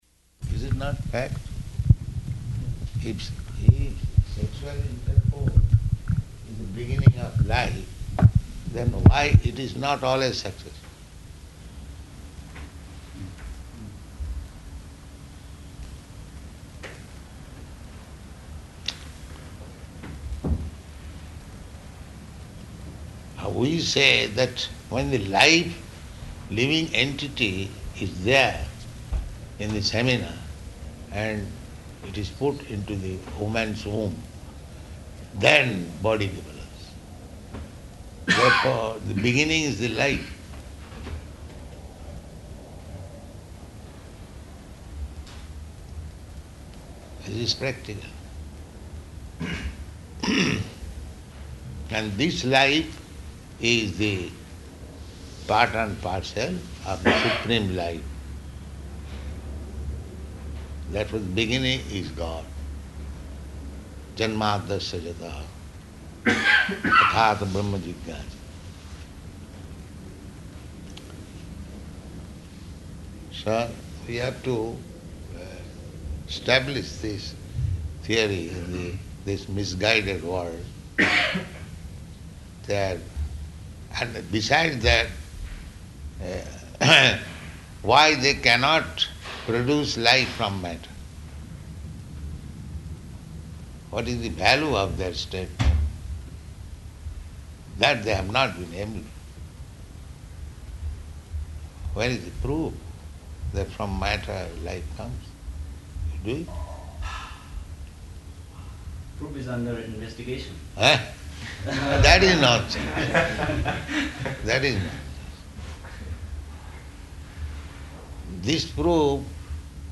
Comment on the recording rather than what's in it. -- Type: Conversation Dated: February 28th 1975 Location: Atlanta Audio file